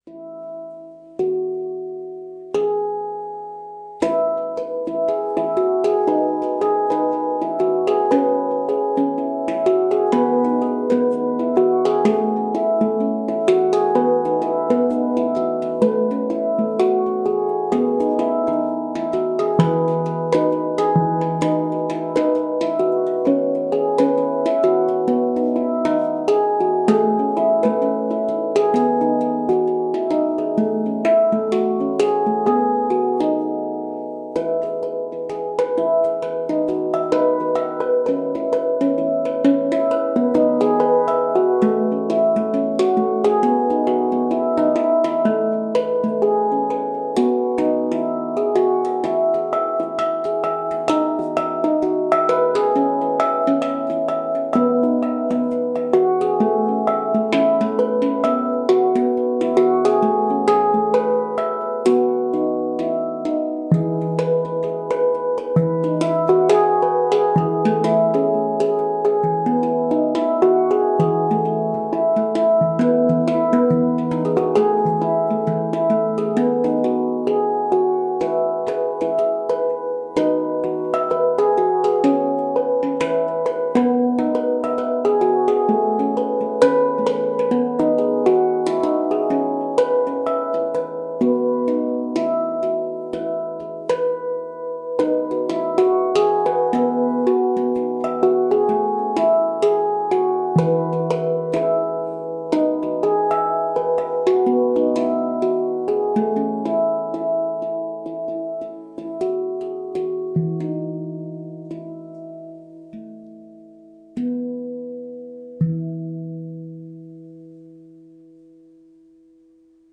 E A B C# Eb E F# G# B E